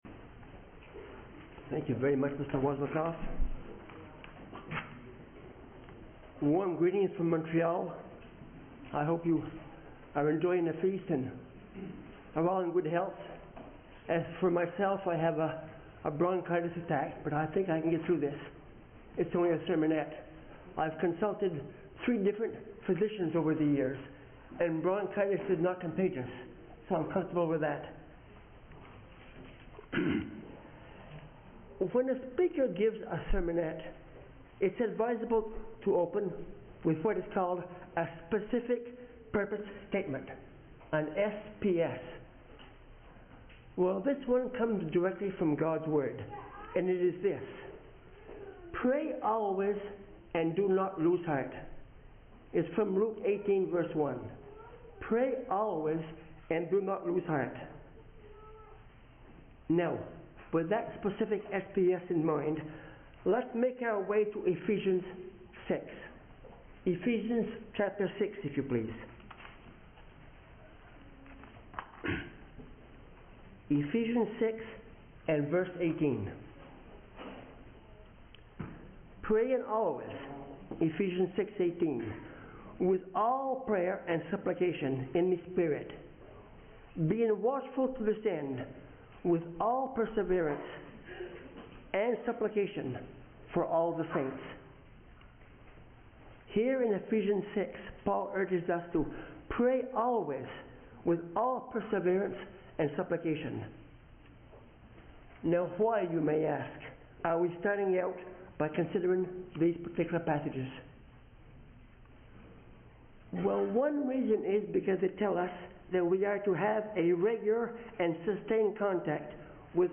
Pray Always - Sermonette
This sermon was given at the Midland, Ontario 2024 Feast site.